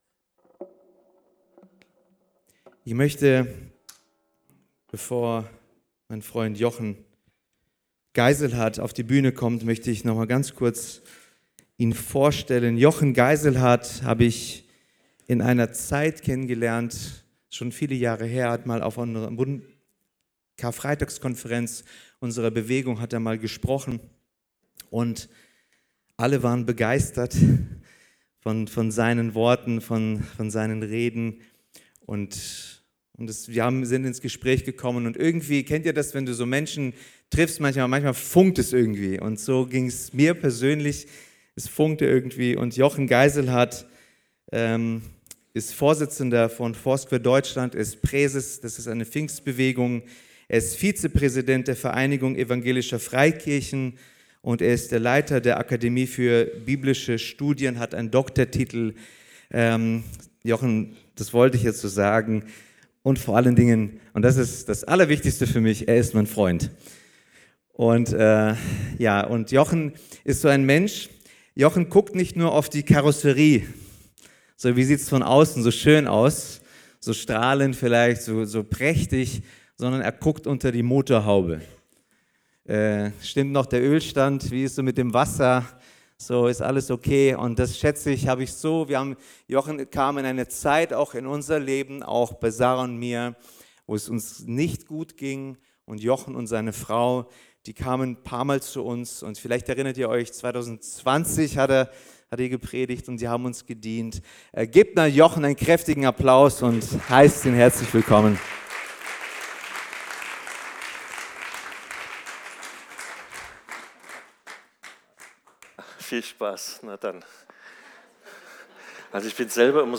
Sonntagspredigten
Wöchentliche Predigten des Christlichen Gemeindezentrums Albershausen